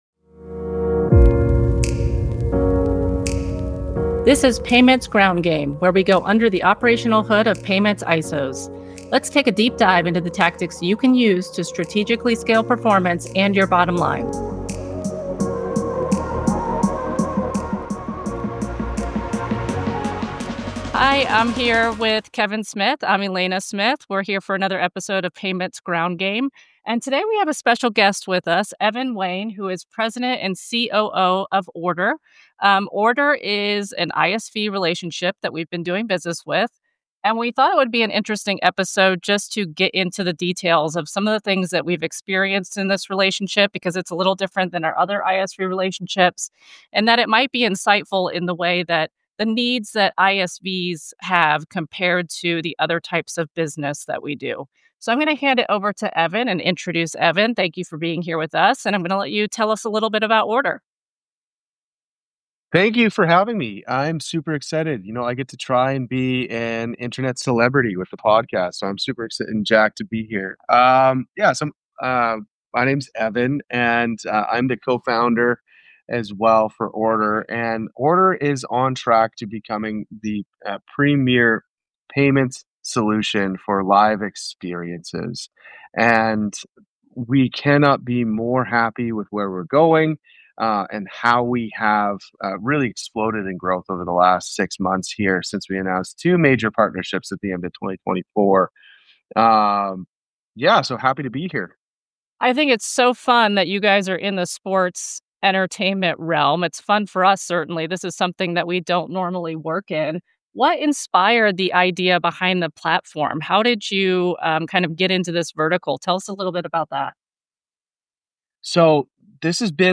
The conversation dives into how Ordr identified and tackled the unique challenges that sports teams and live event organizations face—moving beyond legacy payment systems to provide seamless, data-driven solutions that actually improve the fan experience and back-office operations.